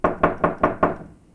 doorknock.ogg